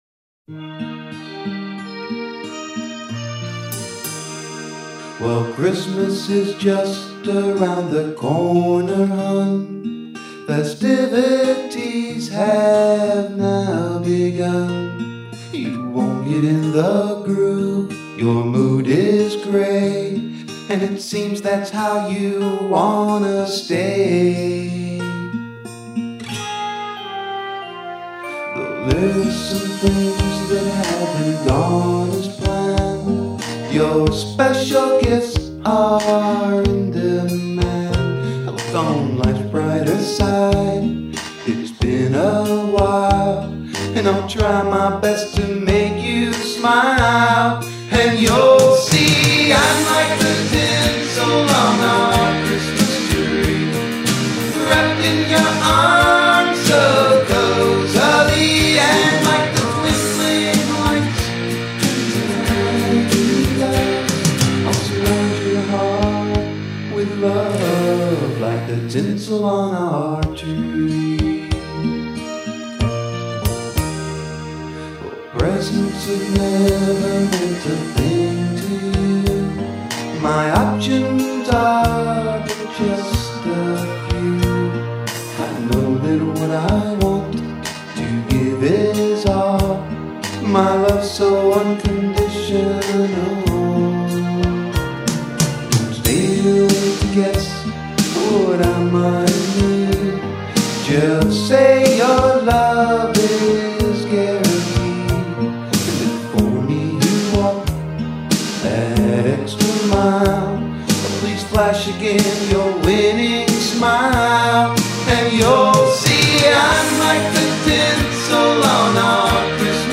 CHRISTMAS SONG
Remastered version